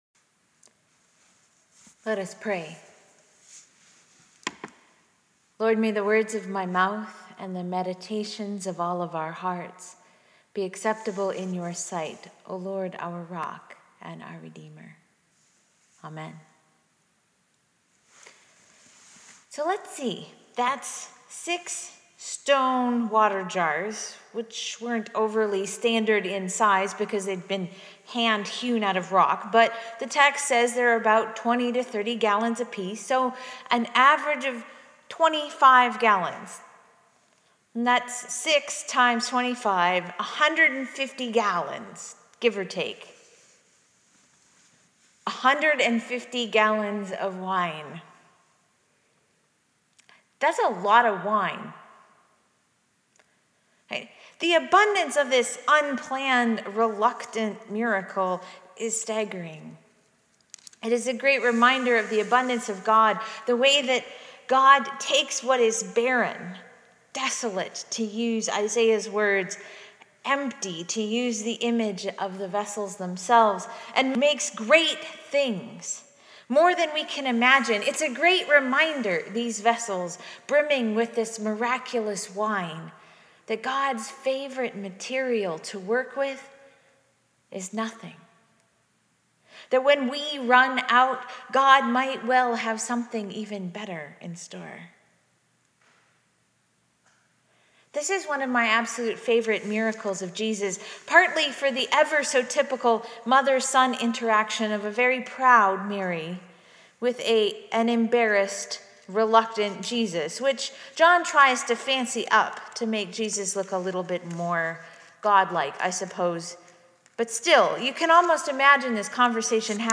The texts for Sunday, January 17, 2015 (2nd Sunday after Epiphany): Isaiah 62:1-5; Psalm 36:5-10; 1 Corinthians 12:1-11; and John 2:1-11